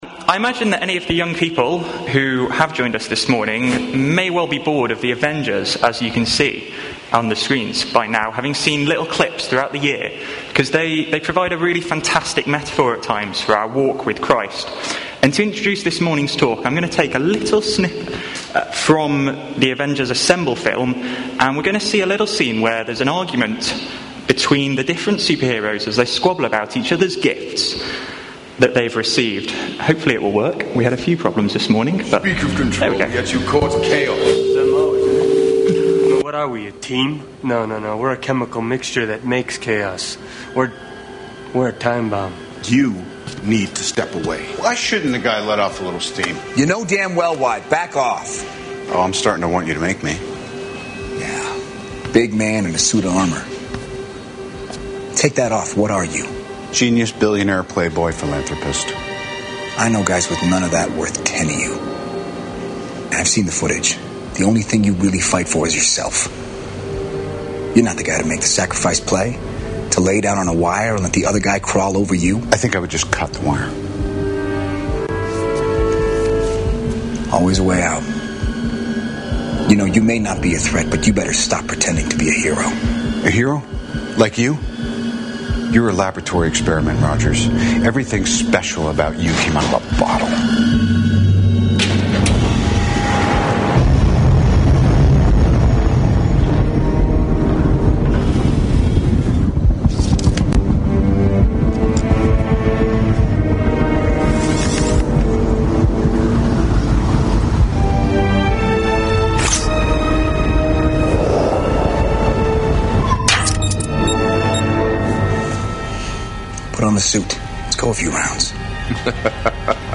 Bible Text: 1 Corinthians 12-1-11 | Preacher: